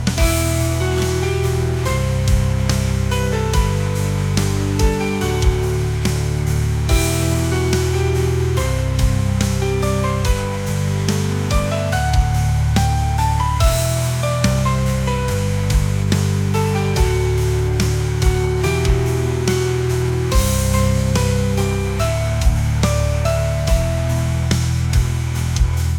pop | rock | ambient